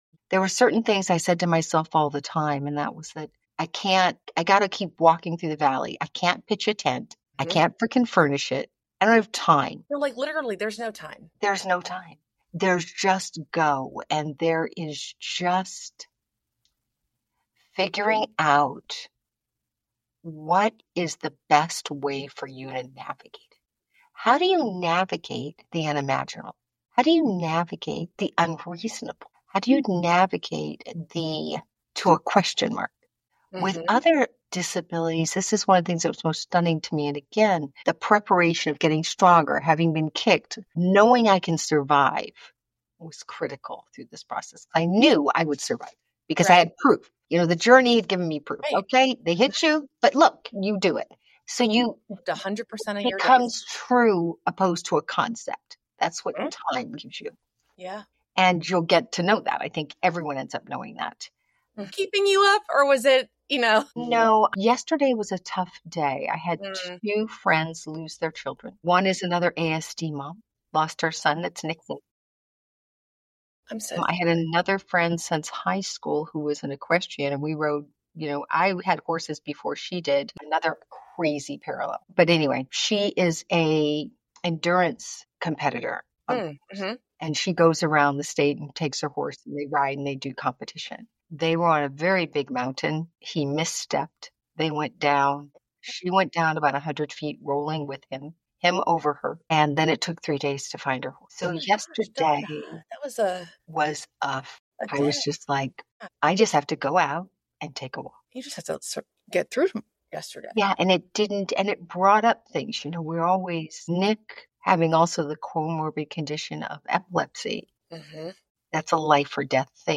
In this moving conversation